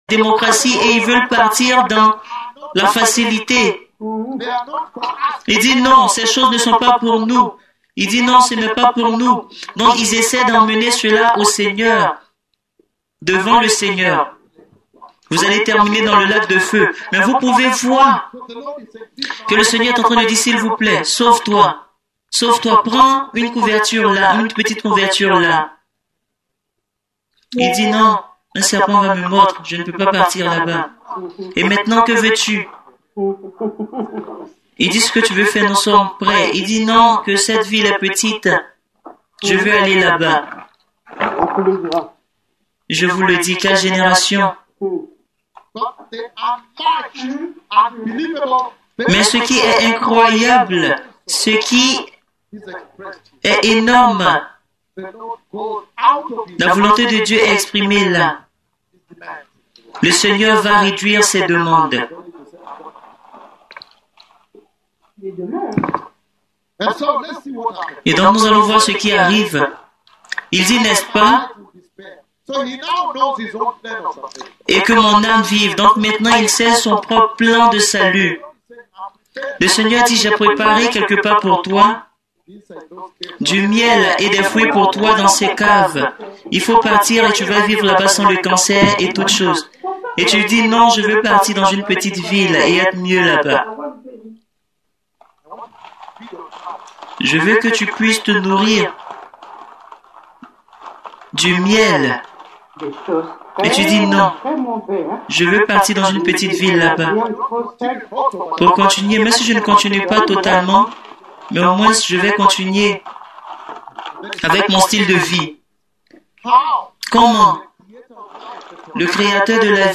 MÉGA CONFÉRENCE DE DISCIPOLAT POUR LE LEADERSHIP INTERNATIONAL | 3 Novembre 2023
SUPER MÉGA SERVICE MONDIAL DE RÉVEIL QUI PRÉPARE LES NATIONS POUR LE RETOUR DU MESSIE, SERVICE CONDUIT PAR LES DEUX PUISSANTS PROPHÈTES DU SEIGNEUR (CELUI QUI A PROPHÉTISÉ LE CORONA VIRUS ET LA RAVAGEUSE PLAIE DES SAUTERELLES).